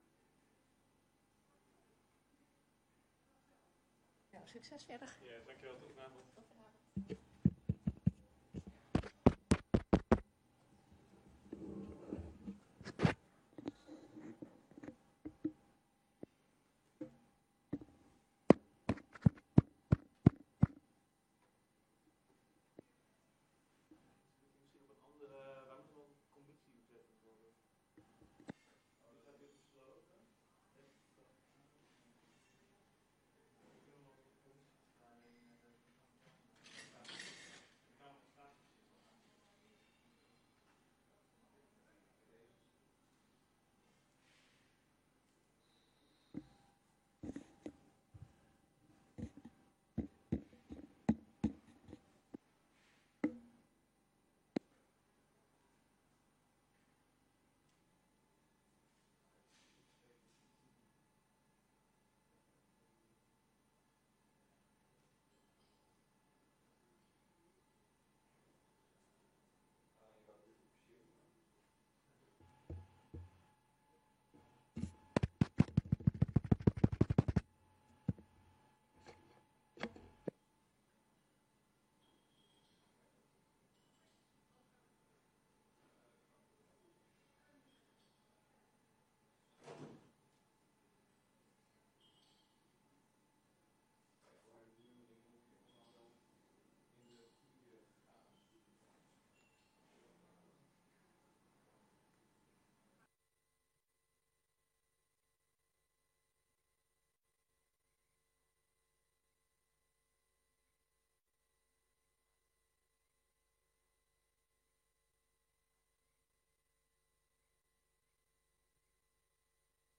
Vergadering van de commissie Samenlevingszaken op maandag 11 maart 2024, om 19.30 uur in kamer 83 van het gemeentehuis.